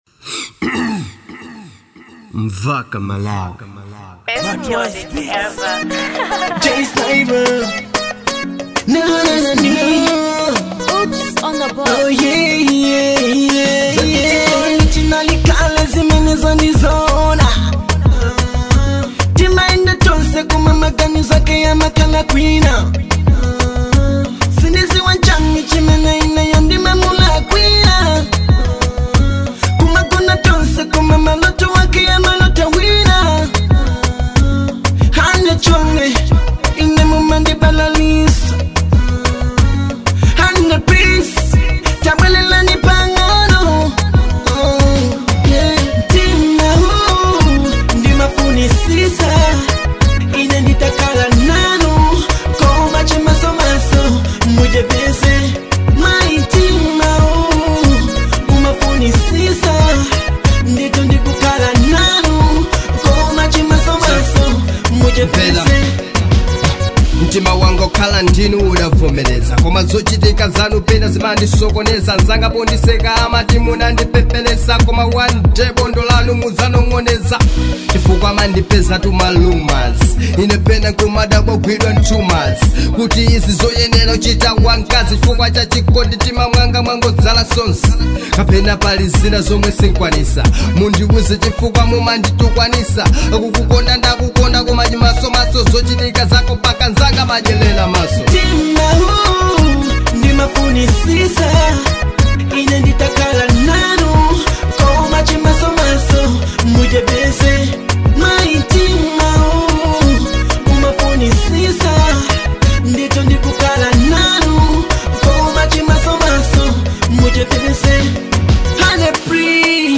type: love song